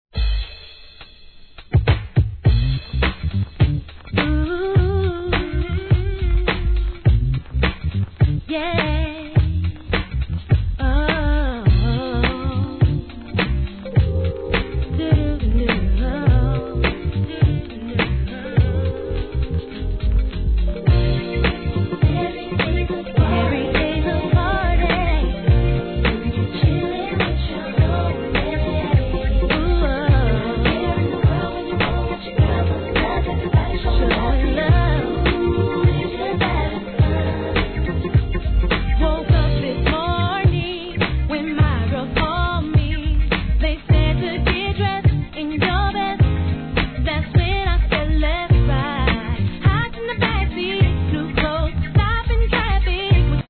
HIP HOP/R&B
流れる様な気持ちよいリズムにピアノのメロディーが入る極上スムース・ダンサー♪